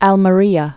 (ălmə-rēə, älmĕ-)